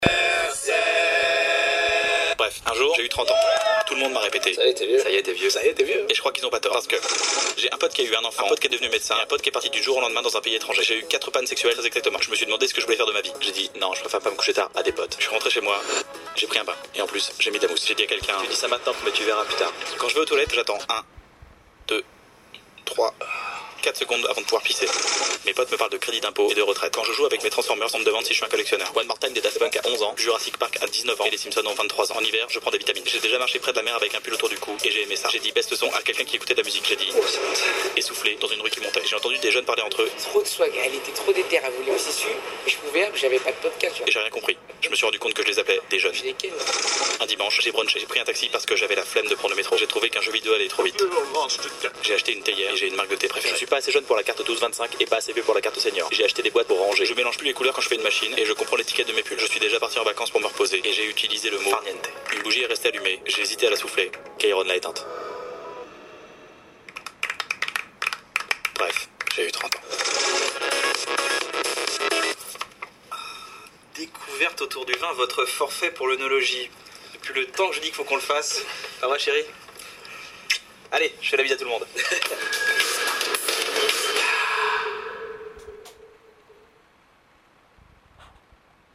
Alors, la diction est identifiable entre toutes, les mots, les images et l’humour se bousculent.
Un peu vite quand même !